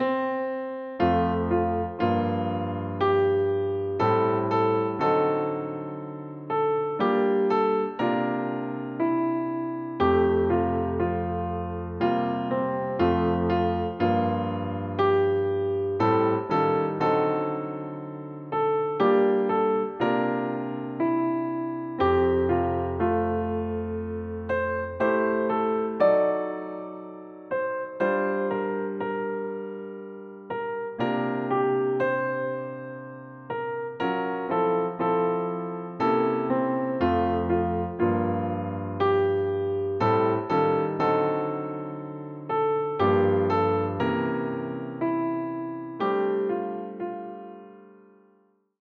Besetzung: Klavier